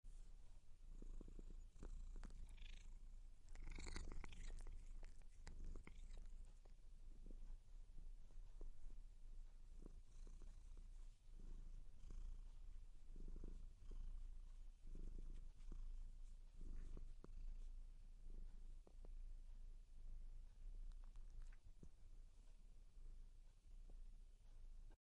猫的叫声 " 猫喜欢自己
标签： 洗澡
声道立体声